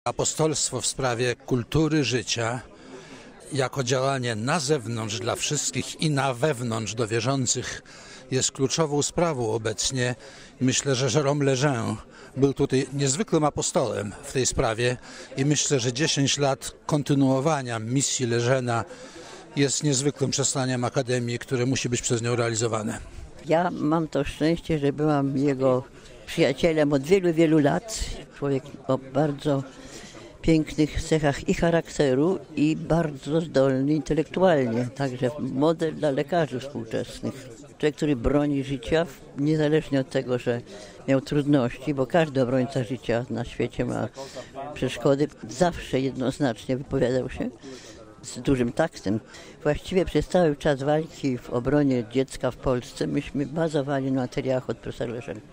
Dzisiaj przypomniano sylwetkę pierwszego prezesa Akademii, wybitnego francuskiego genetyka, profesora Jérôma Lejeune. Mówią polscy członkowie Akademii Pro Vita